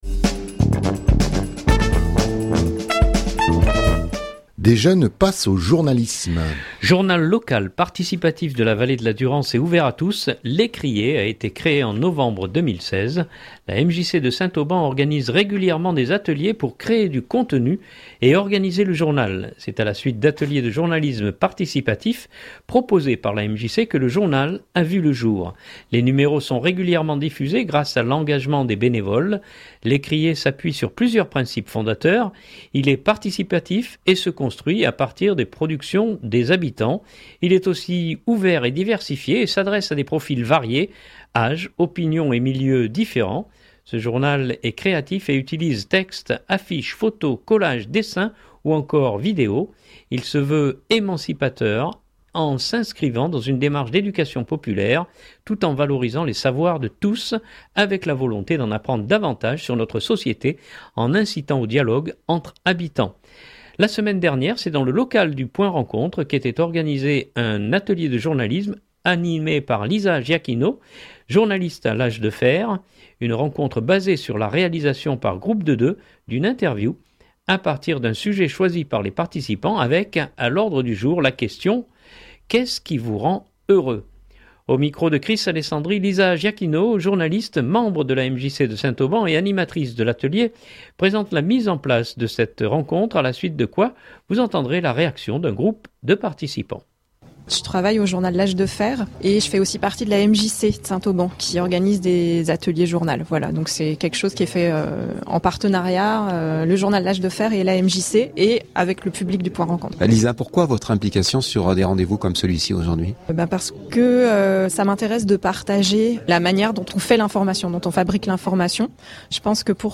à la suite de quoi vous entendrez la réaction d’un groupe de participants.